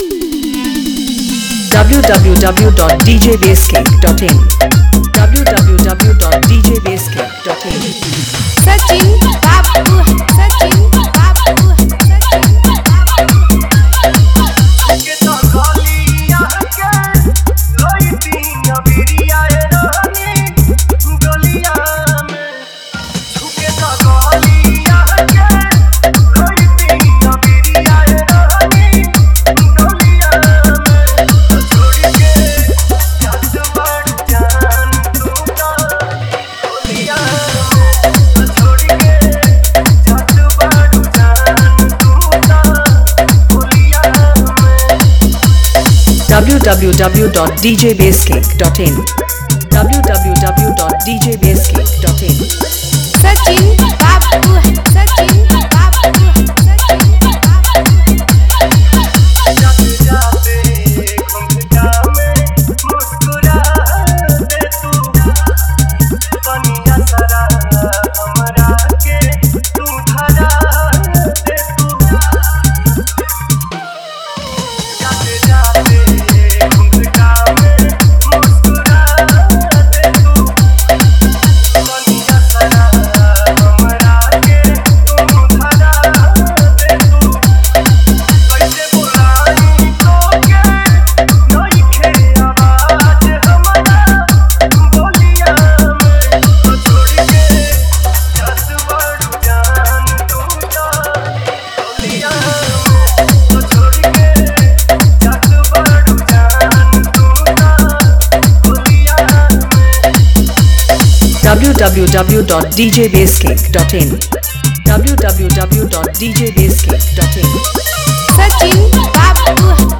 Holi Dj Remix Song Download